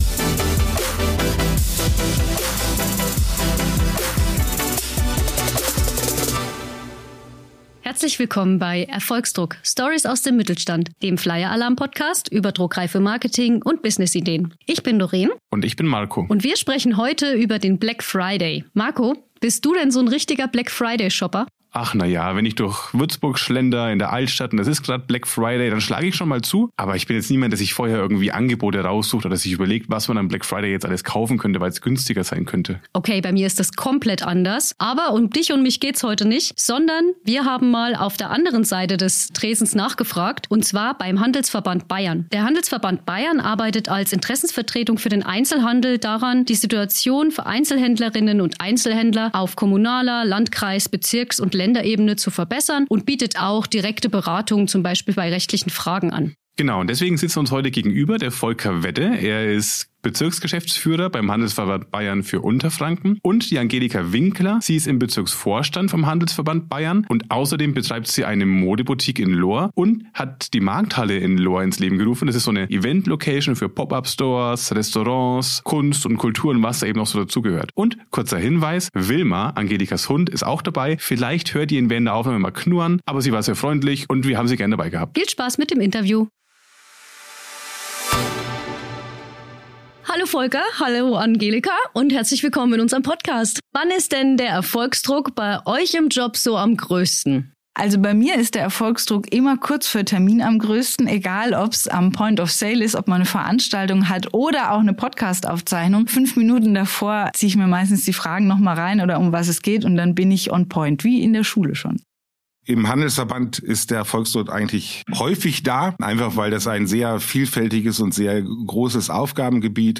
Im Gespräch teilen die beiden ihre Einschätzung zum Black Friday aus Sicht des regionalen Einzelhandels, geben Einblicke in erfolgreiche Praxisbeispiele und verraten, wie kleine Händlerinnen und Händler den Aktionstag strategisch clever für sich nutzen können.